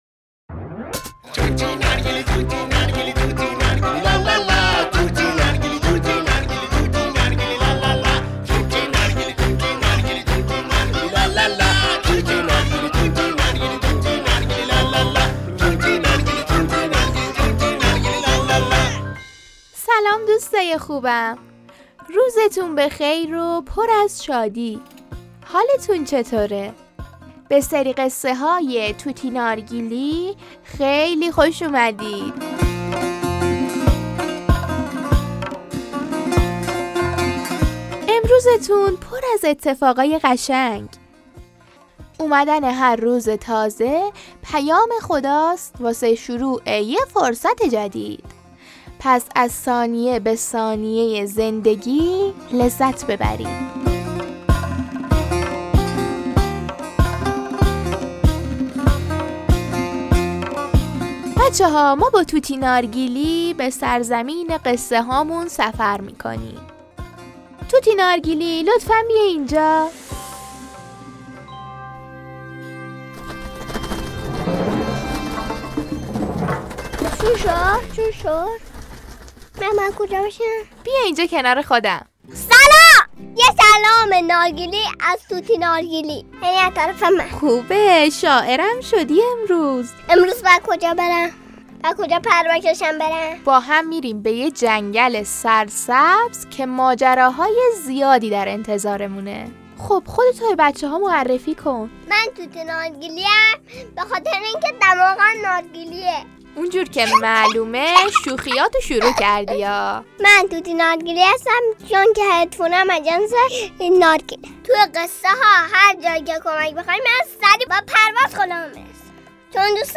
درمان ترس در کودکان - قصه صوتی کودکانه طوطی نارگیلی - خرگوش کوچولوی قصه ما دچار ترس بزرگی میشه و شروع به فرار از ترسش میکنه.